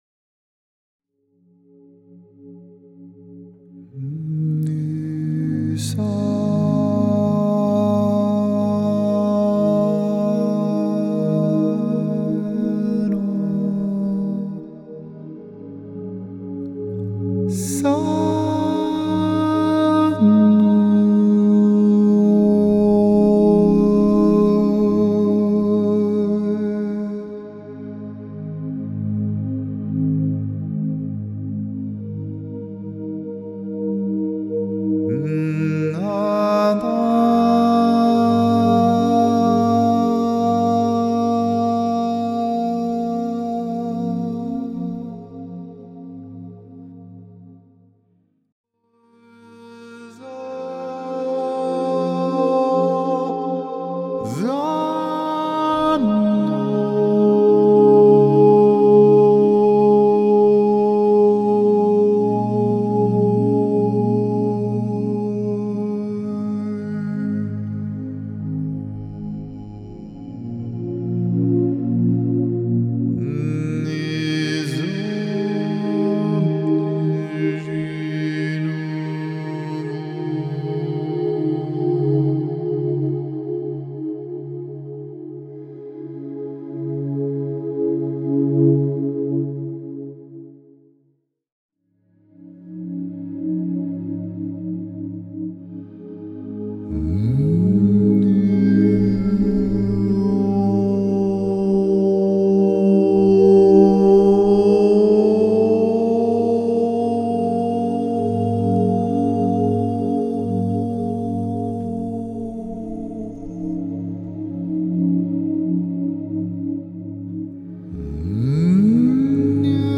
deep and transformational music